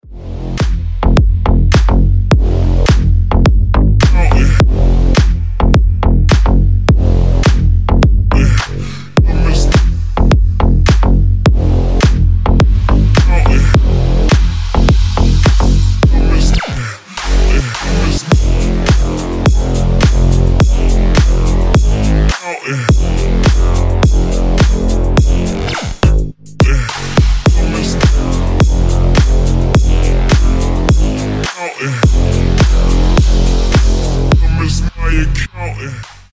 # Клубные Рингтоны » # Громкие Рингтоны С Басами
# Танцевальные Рингтоны